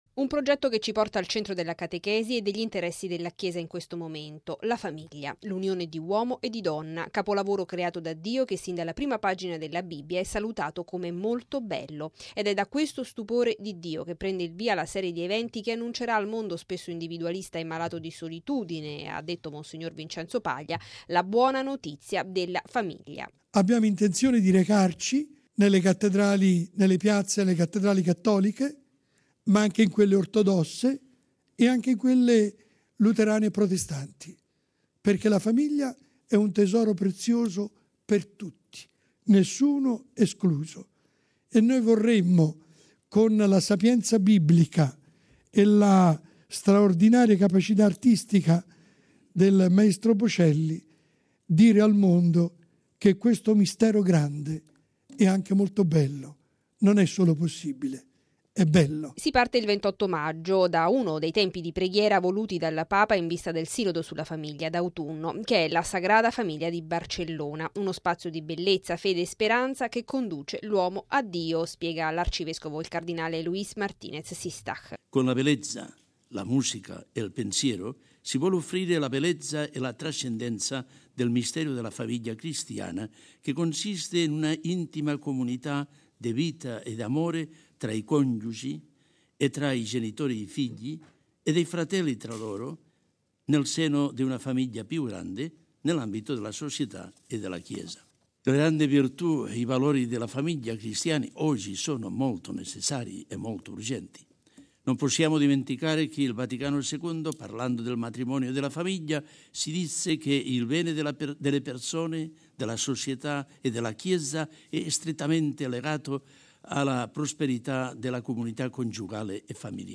A presentarlo nella Sala Stampa vaticana, mons. Vincenzo Paglia, presidente del Pontificio Consiglio per la Famiglia, curatore della realizzazione, e il maestro Andrea Bocelli.